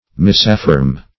misaffirm \mis`af*firm"\ (m[i^]s`[a^]f*f[~e]rm"), v. t. To affirm incorrectly.